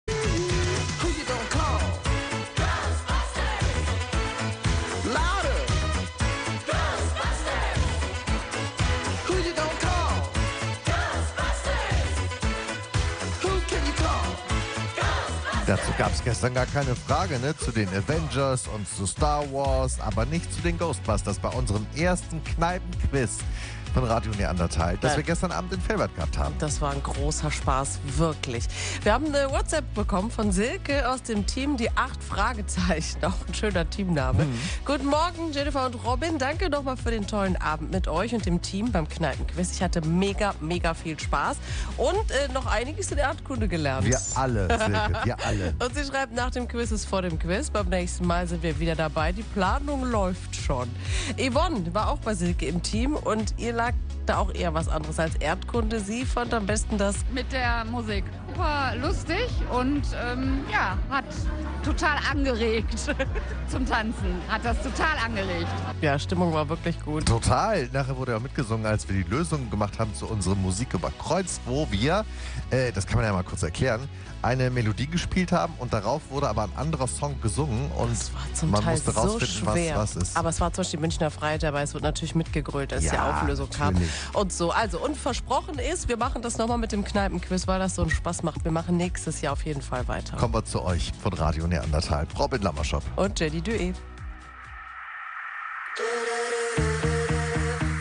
Das erste "Radio Neandertal Kneipenquiz" im Restaurant "Da Vinci" in Velbert. Dreizehn Teams hatten jede Menge Spass beim Rätseln, Quizzen und Musiktitel über Kreuz erkennen.